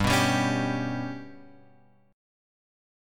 GMb5 chord {3 2 x 0 2 3} chord